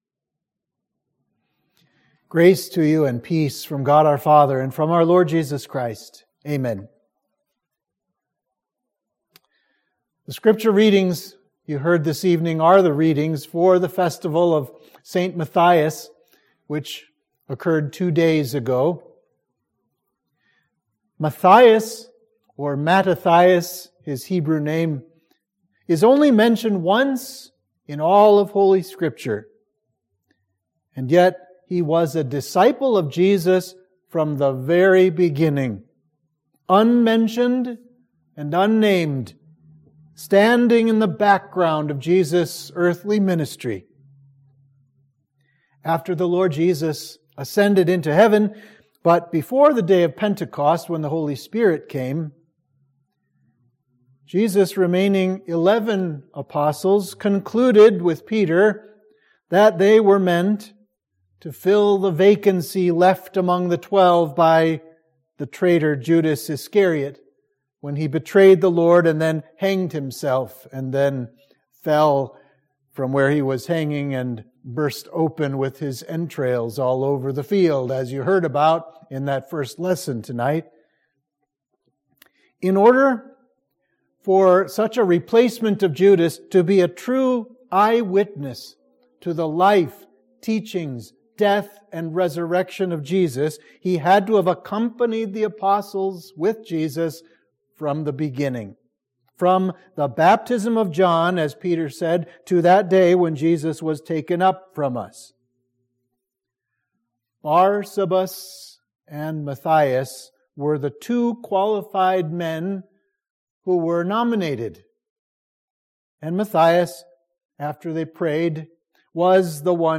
Sermon for the Festival of St. Matthias